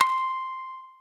shamisen_c1.ogg